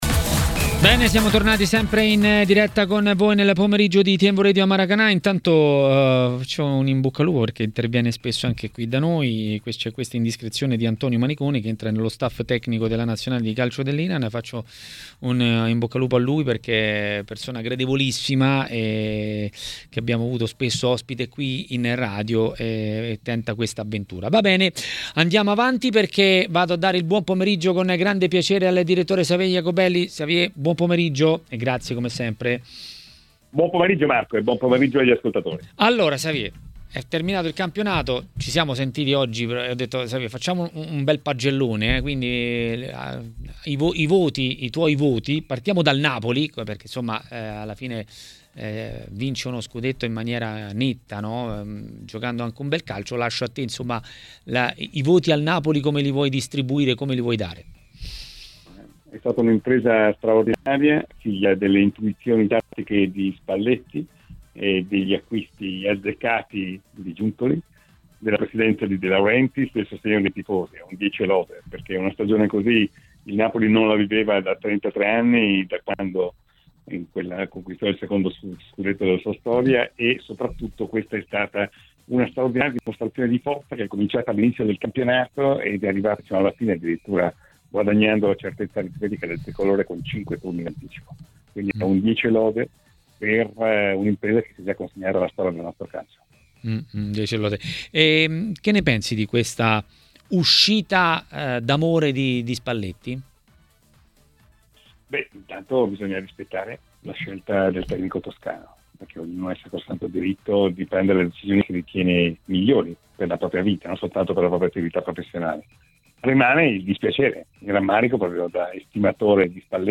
Ospite di TMW Radio, nel corso del programma Maracanà, Xavier Jacobelli ha fatto il punto sulla Serie A.